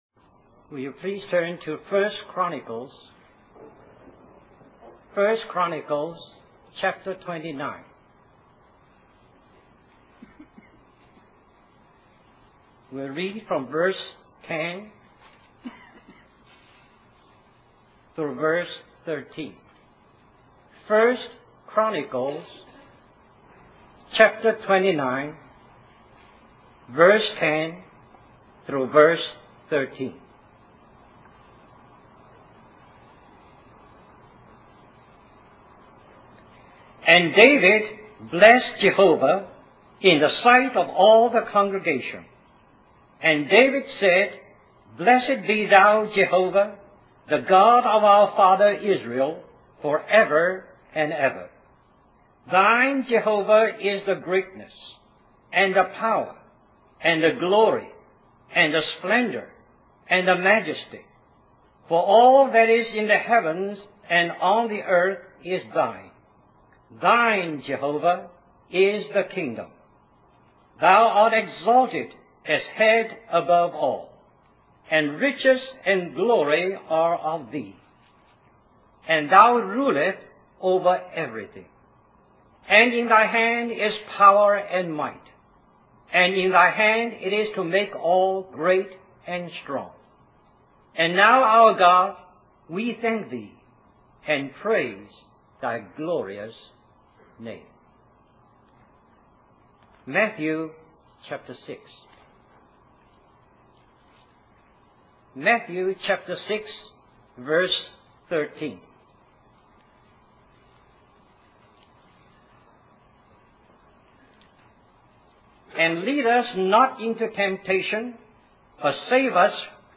1986 Christian Family Conference Stream or download mp3 Summary This message is also printed in booklet form under the title: The Kingdom of God.